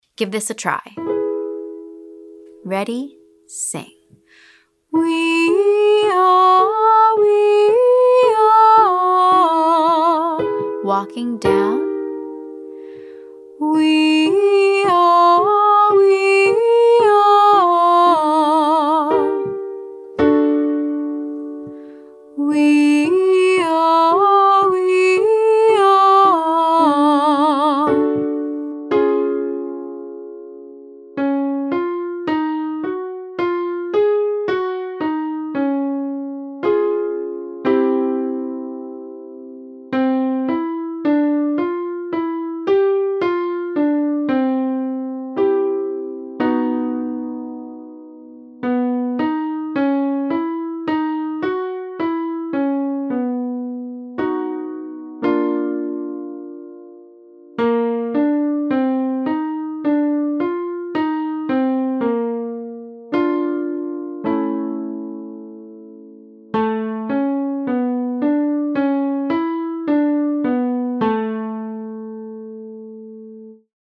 Exercise 3: Jaw Release Wee Yah 1-3,2-4,3-5,4,2 1 (alternate wee yah without chewing jaw)